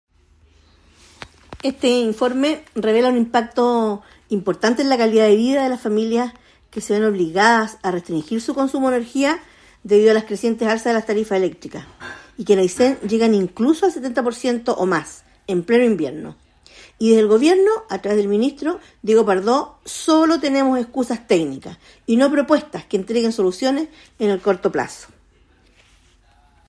Diputada Marcia Raphael